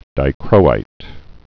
(dī-krōīt)